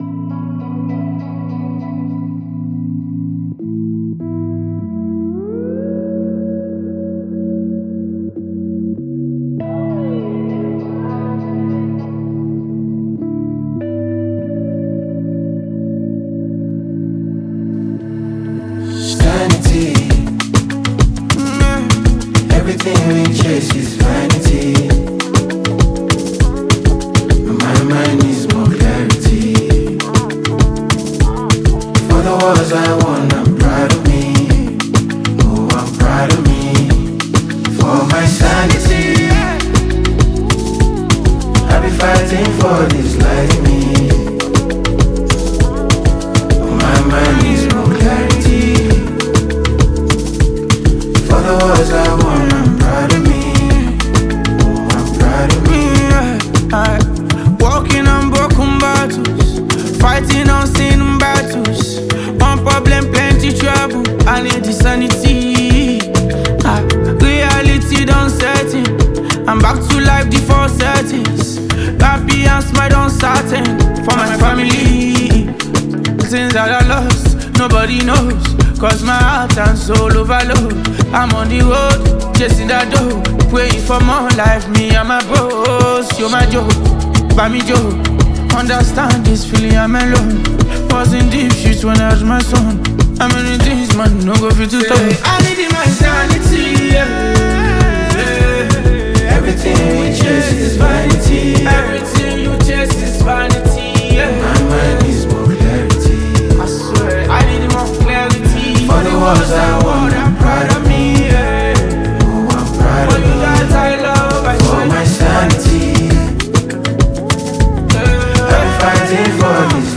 Nigerian Afrobeat